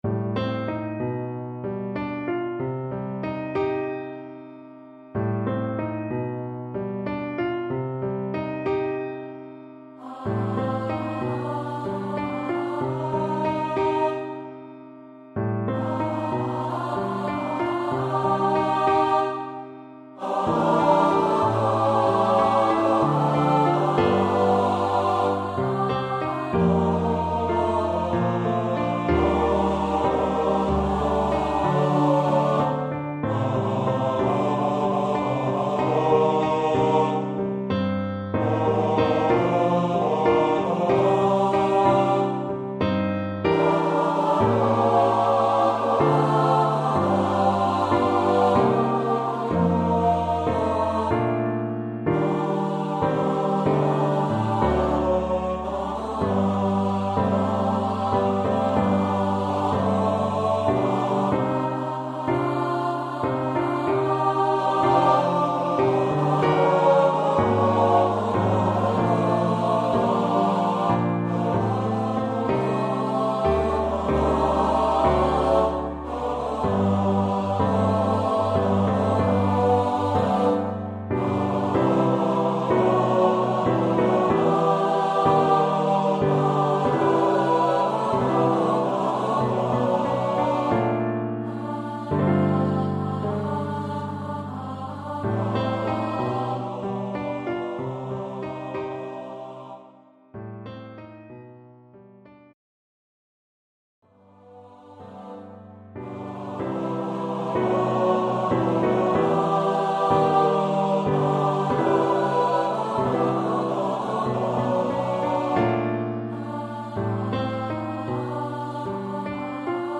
SATB – piano, basse-batterie en option